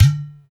80 HI TLK DR.wav